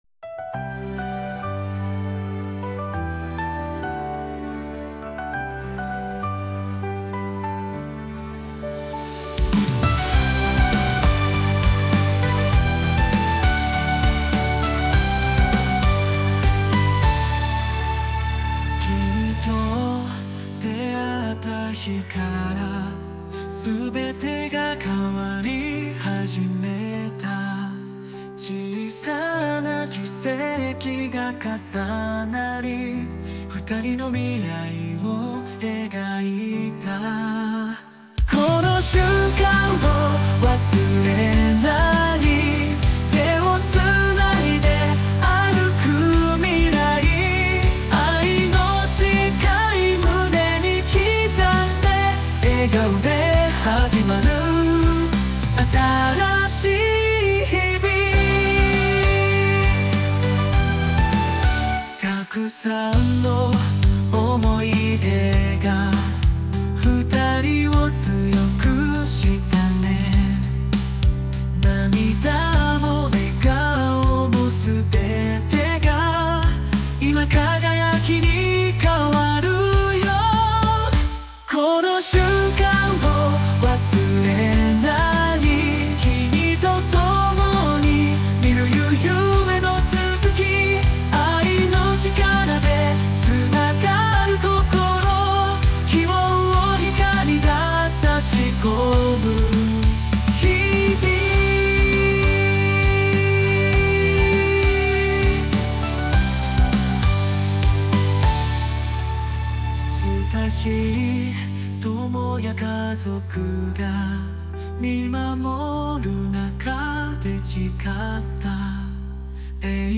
日本語・邦楽、男性ボーカル曲です。